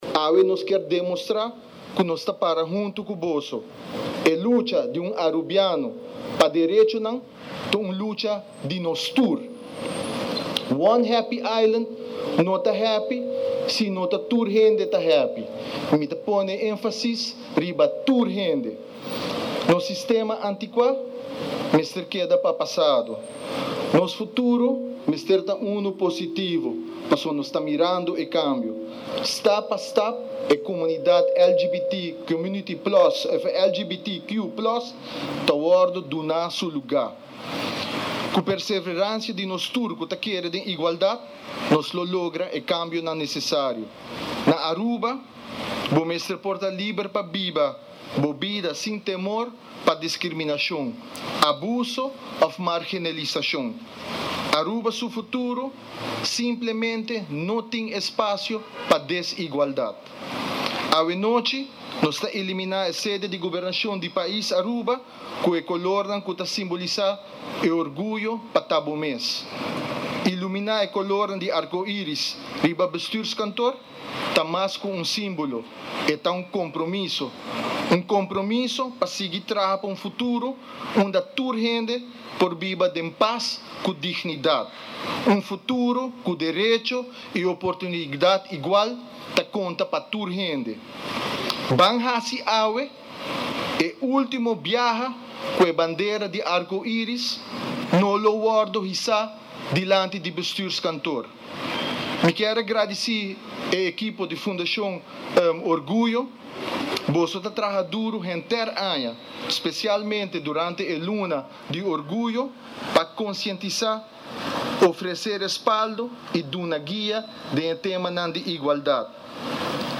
Diabierna anochi a tuma luga un ceremonia dilanti di Bestuurskantoor pa cende luznan Rainbow pa ilumina Bestuurskantoor.  Minister di Medio ambiente y integridad Ursell Arends a hiba palabra na e grupo di LGBTQ+ presente unda cu vocifera y kier pa nan sa cu Partido RAIZ ta mira nan,scucha nan y ta para hunto cu e grupo di LGBTQ+ den comunidad di Aruba.